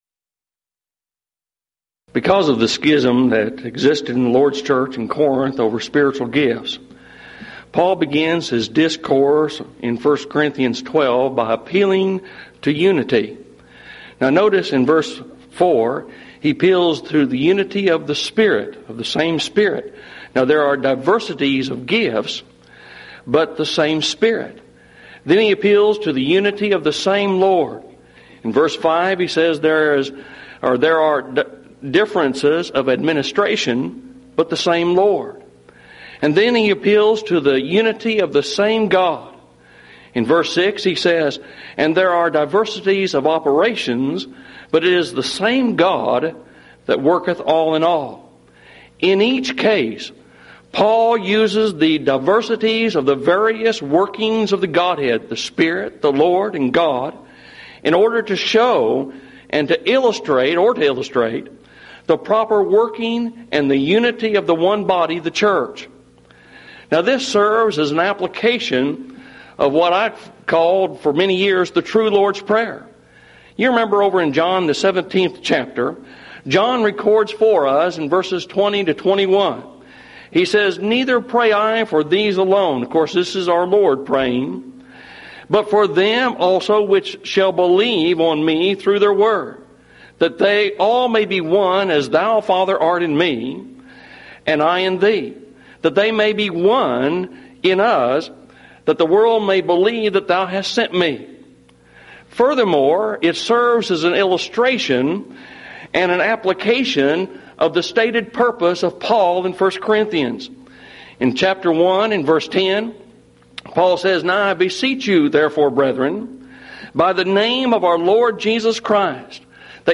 Series: Mid-West Lectures Event: 1997 Mid-West Lectures Theme/Title: God The Holy Spirit